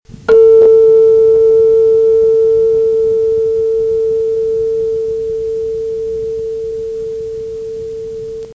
Tuning fork 4
Category: Sound FX   Right: Personal
Tags: Tuning Fork Tuning Fork sounds Tuning Fork clips Tuning Fork sound Sound effect